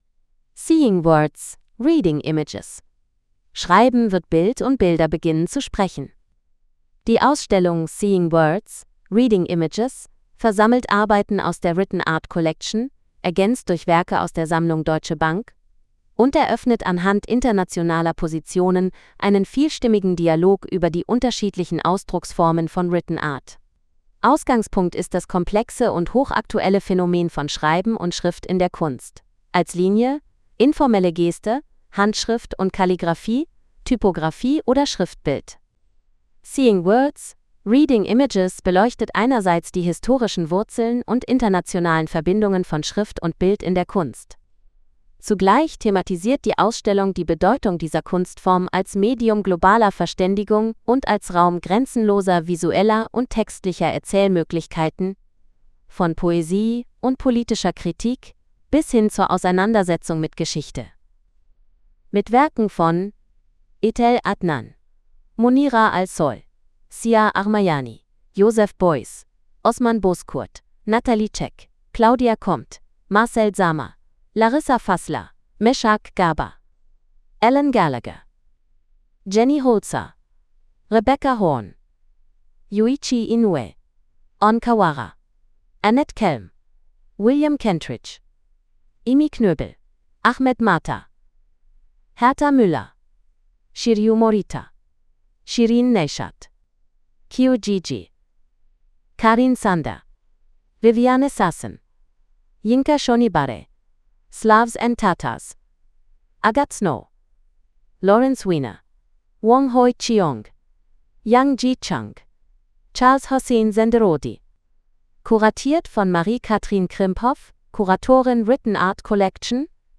Hinweis: Die Audiotranskription ist von einer KI eingesprochen.